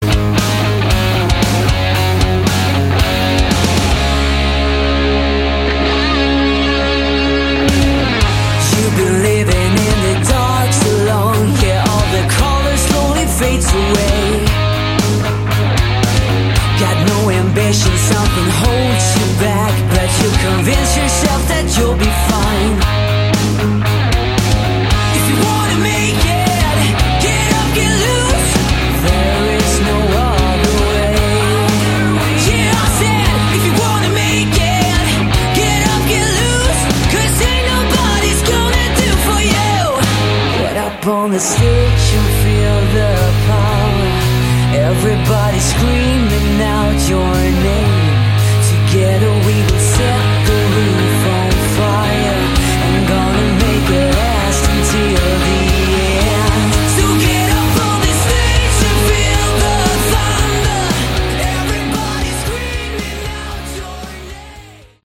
Category: Sleaze Glam / Hard Rock
drums
guitar, Lead vocals
guitar, backing vocals
Bass, backing vocals